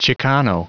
Prononciation du mot chicano en anglais (fichier audio)
Prononciation du mot : chicano